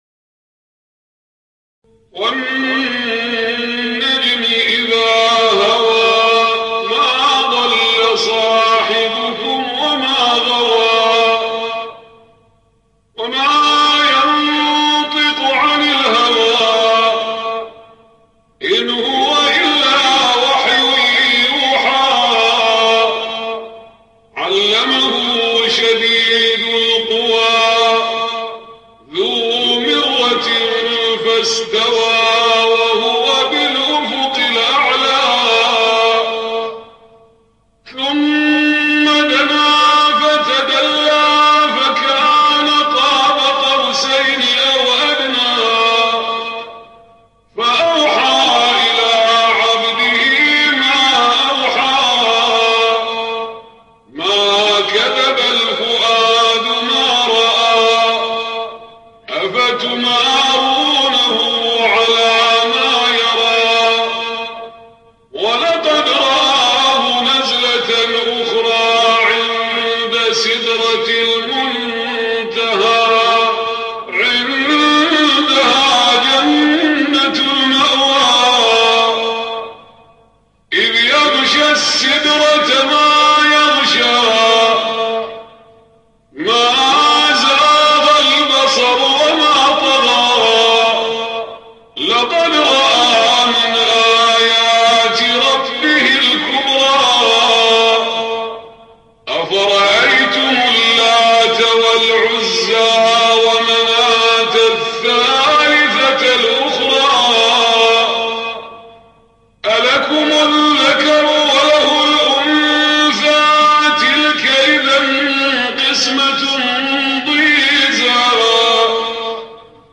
Riwayat Hafs an Asim